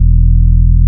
73.03 BASS.wav